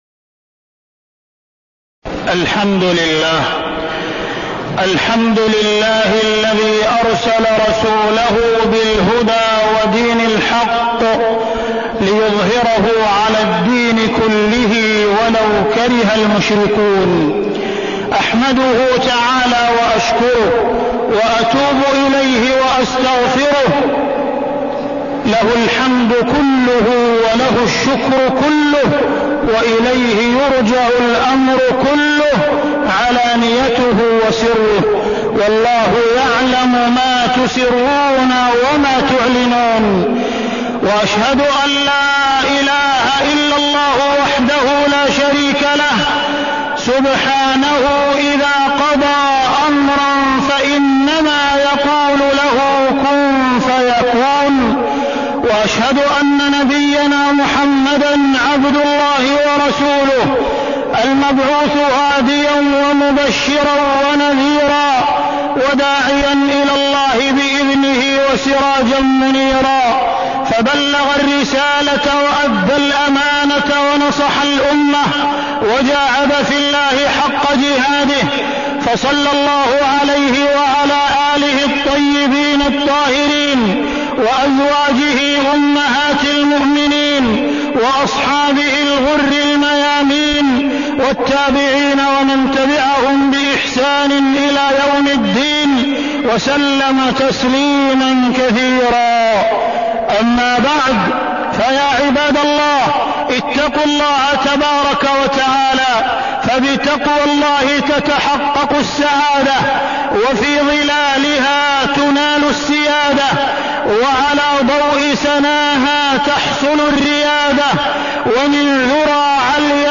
تاريخ النشر ٢٣ ربيع الثاني ١٤١٧ هـ المكان: المسجد الحرام الشيخ: معالي الشيخ أ.د. عبدالرحمن بن عبدالعزيز السديس معالي الشيخ أ.د. عبدالرحمن بن عبدالعزيز السديس الصحوة الإسلامية والحضارة المادية